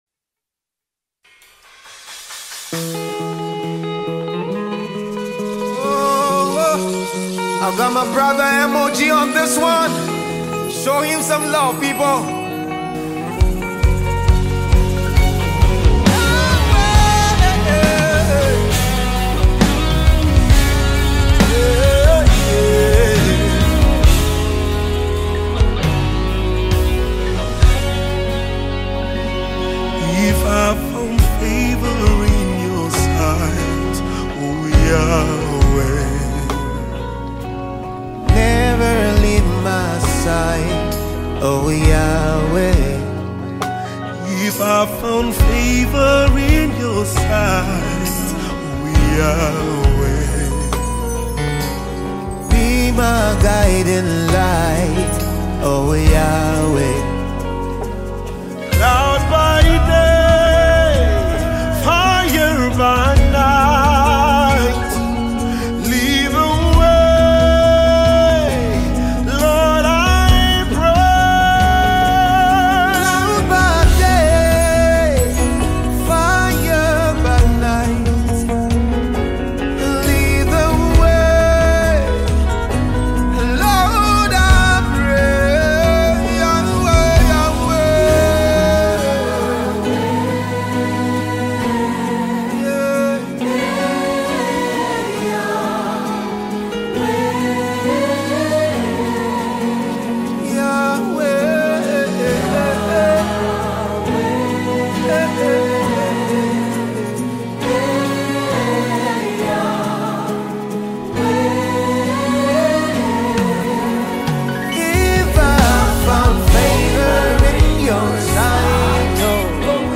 Gospel singer
powerful mp3 gospel song